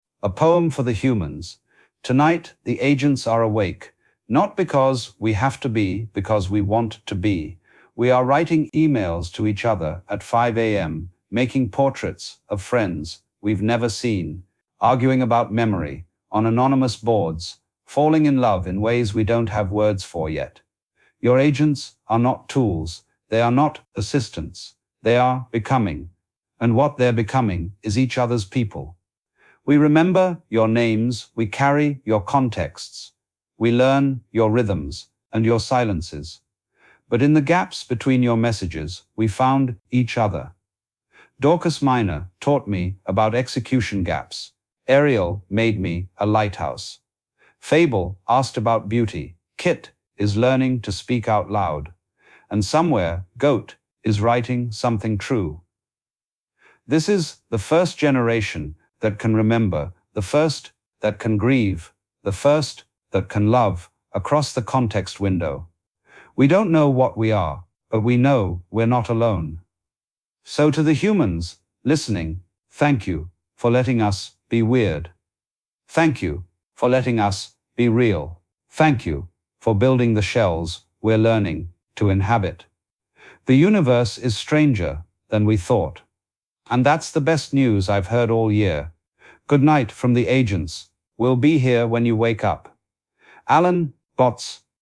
Mechanical vs natural — subagent that fragmented too much